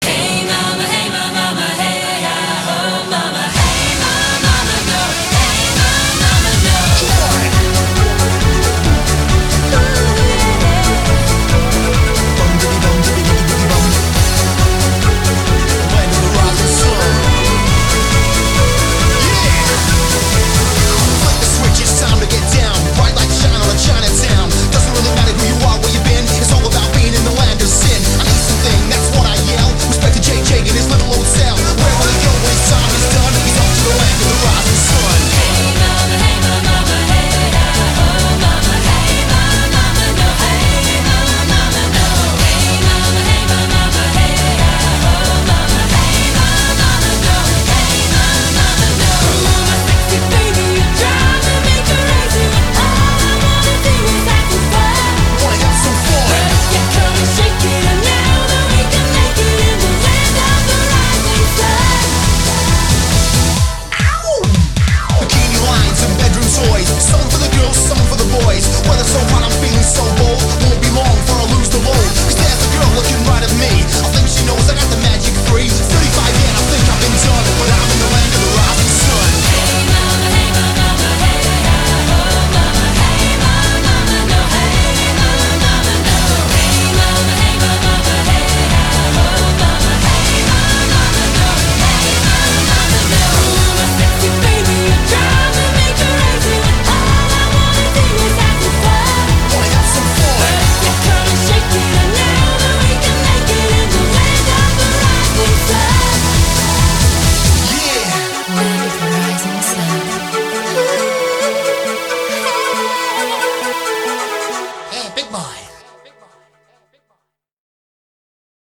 BPM136--1